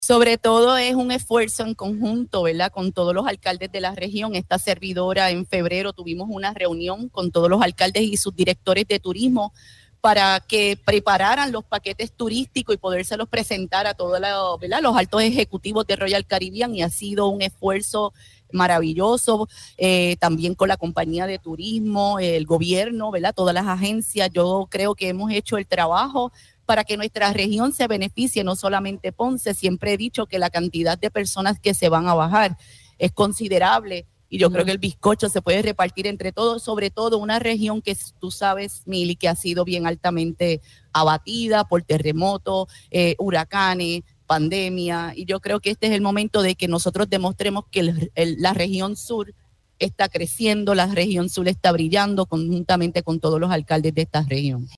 Por su parte, en entrevista para este espacio la alcaldesa Marlese Sifre se mostró emocionada con el acontecimiento.